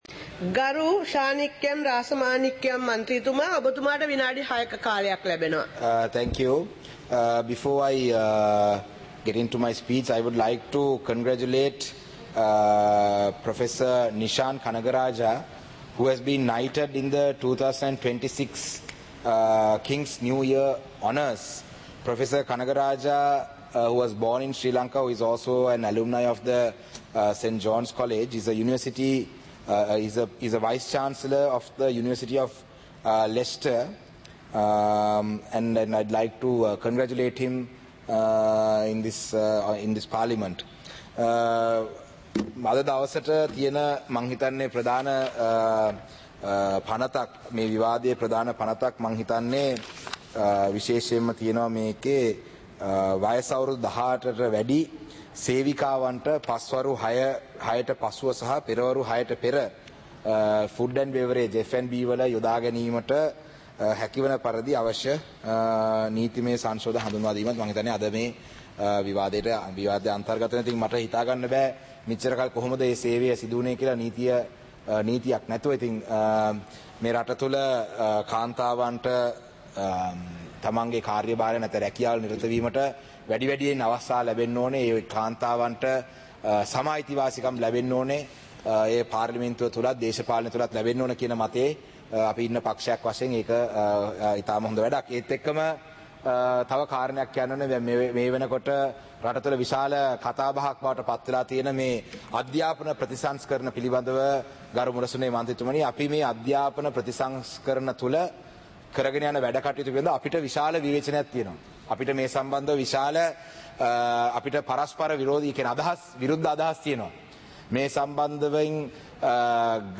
இலங்கை பாராளுமன்றம் - சபை நடவடிக்கைமுறை (2026-01-09)